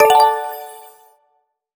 SFX
Scoring a Point.wav